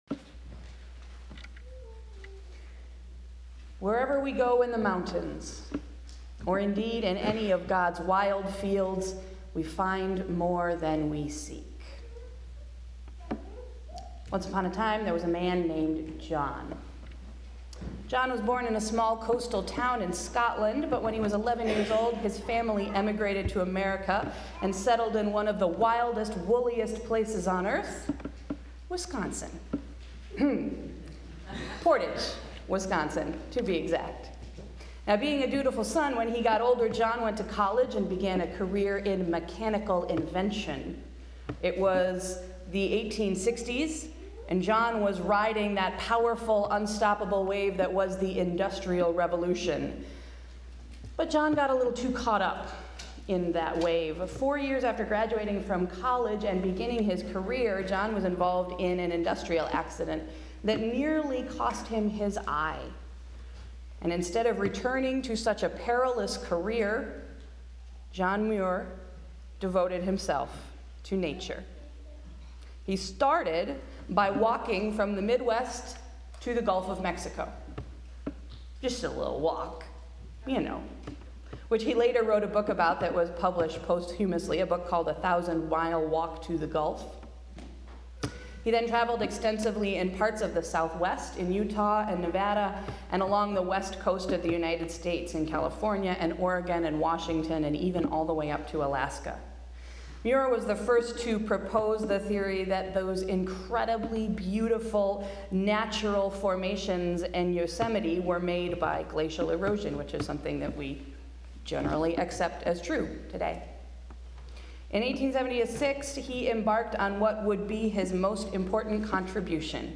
Sunday’s sermon: Wilderness Walking and Comfort Calling